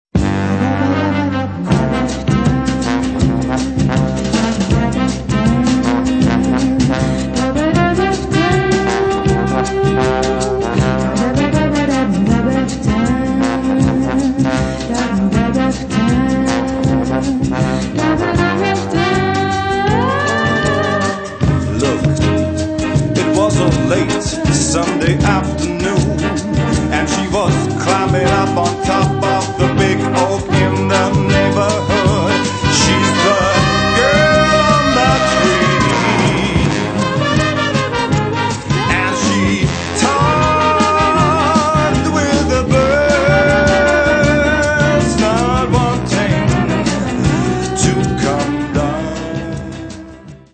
Das klingt doch nach tiefsten Siebziger Jahren!